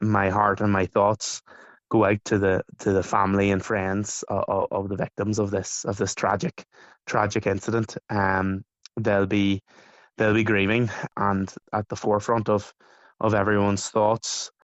Local Councillor Adam Gannon says the community has been rocked by the news: